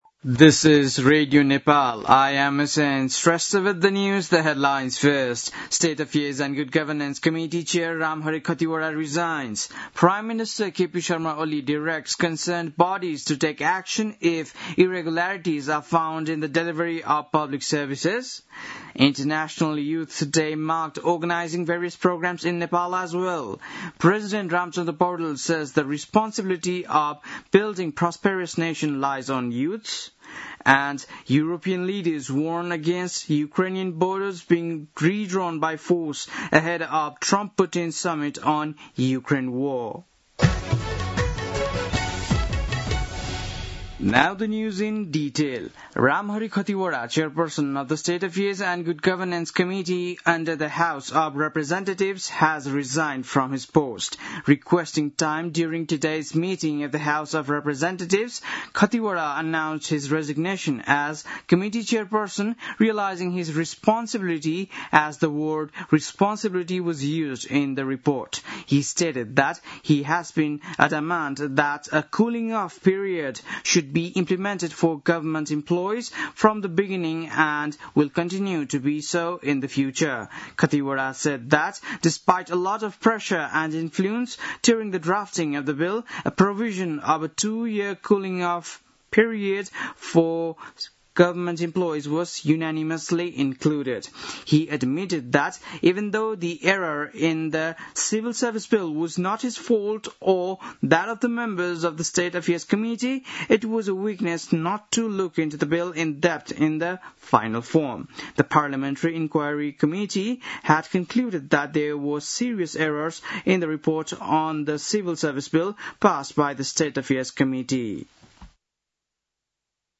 बेलुकी ८ बजेको अङ्ग्रेजी समाचार : २७ साउन , २०८२